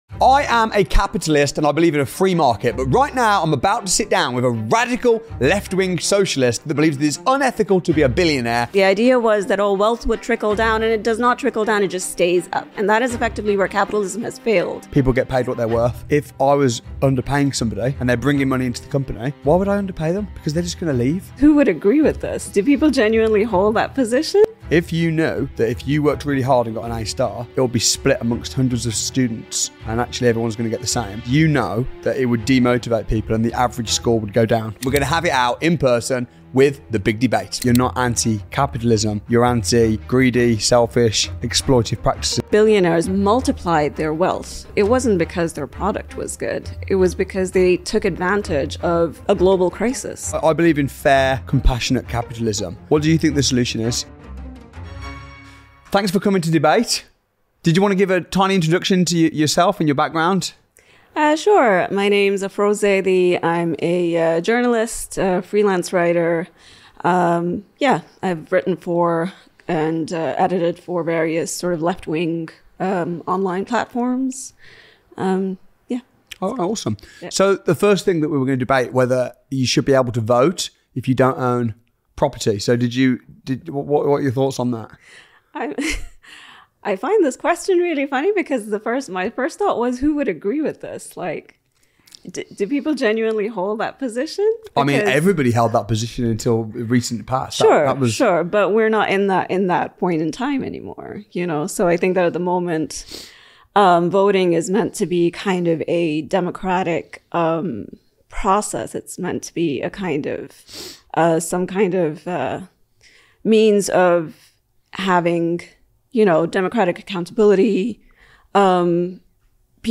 Socialist Vs Capitalist | Full Heated Debate